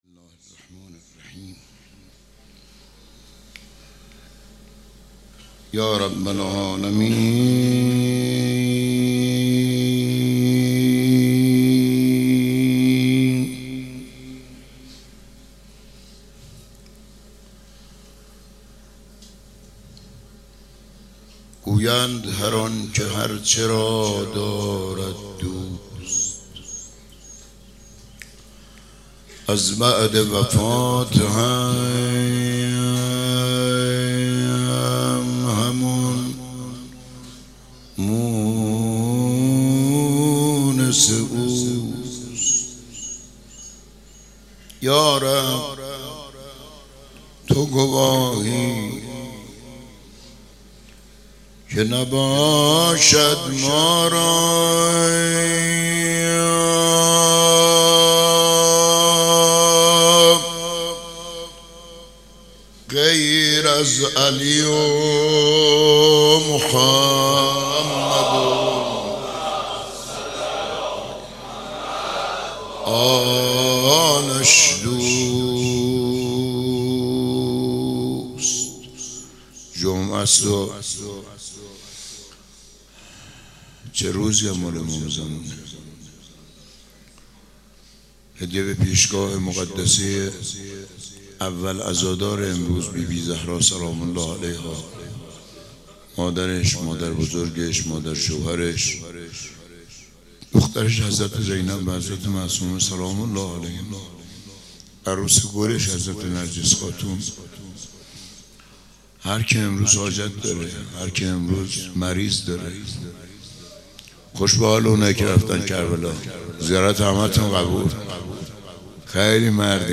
شب 28 صفر - روضه